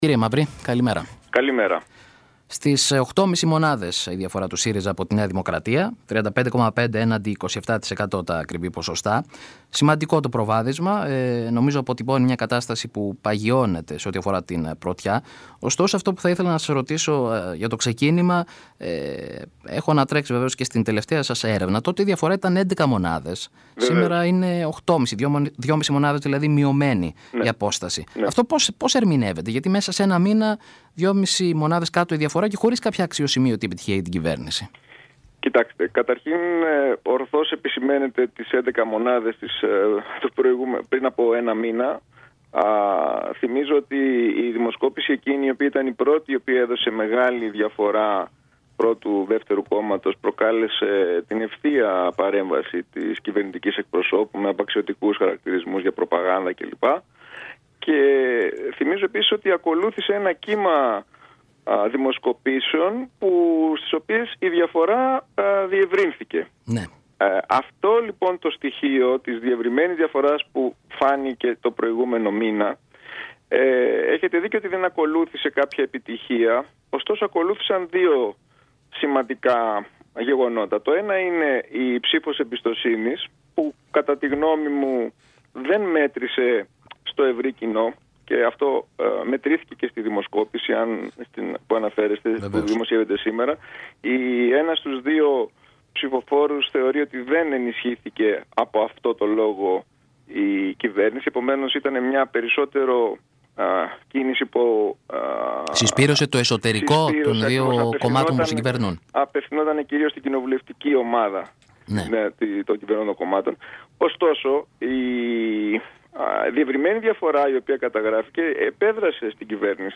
μίλησε στον ραδιοφωνικό σταθμό «Στο Κόκκινο»